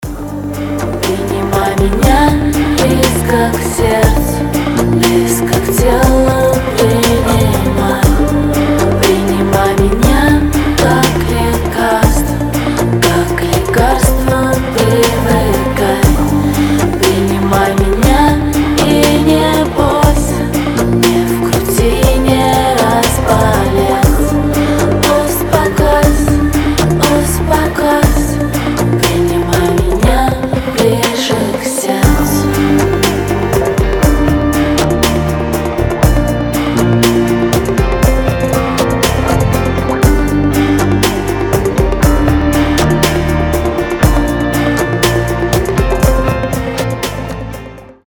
• Качество: 320, Stereo
поп
чувственные
романтичные
медляк